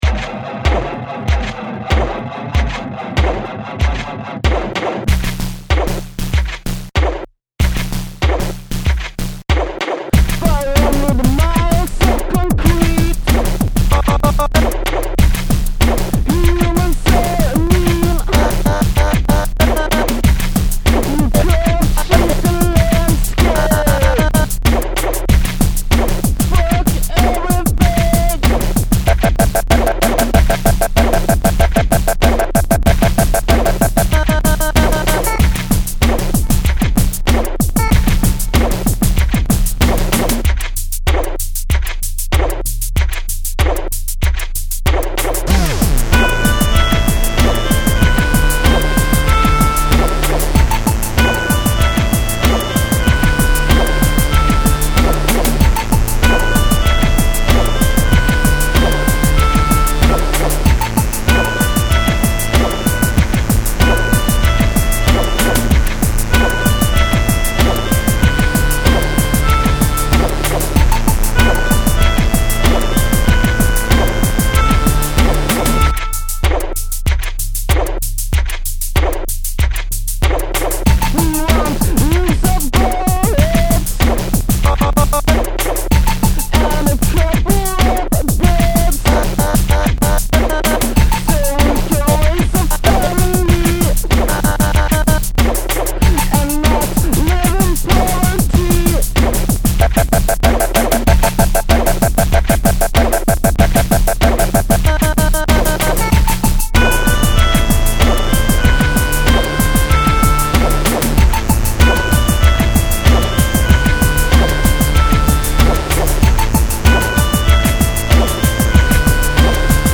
surprising howling black metal outro